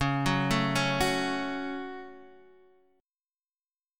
C#sus4#5 chord